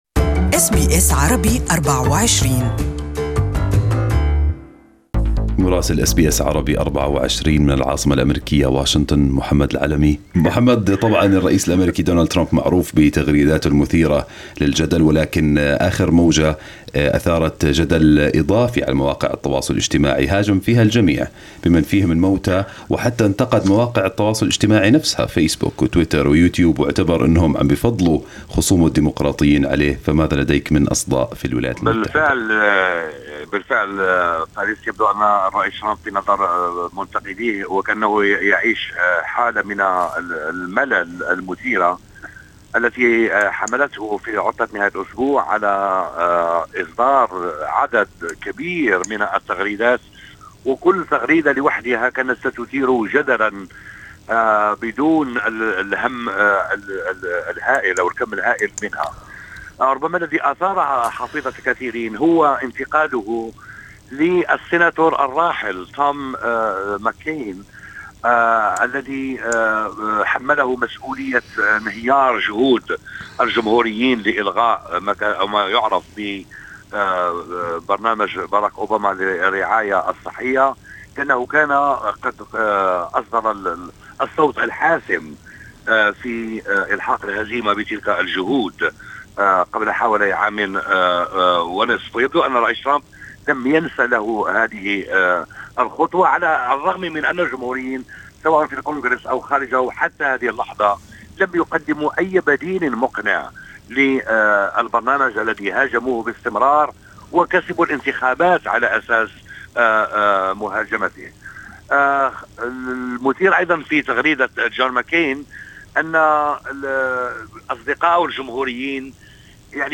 Listen to the full report from Washington in Arabic above